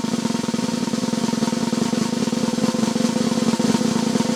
drumroll.ogg